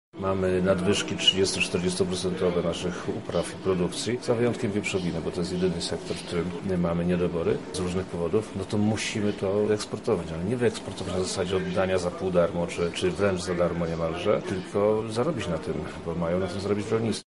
Zarząd Krajowego Stowarzyszenia Eksporterzy Rzeczypospolitej Polskiej zorganizował w Lublinie Forum Eksportu 2019. Głównym tematem były powody wzrostu eksportu w Polsce.
O wsparciu polskiego handlu mówi wojewoda lubelski Przemysław Czarnek: